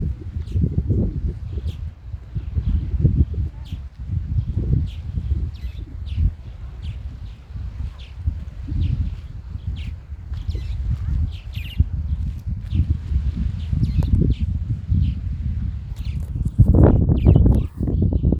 Andorinha-do-sul (Progne elegans)
Nome em Inglês: Southern Martin
Detalhada localização: Costanera de Miramar.
Condição: Selvagem
Certeza: Fotografado, Gravado Vocal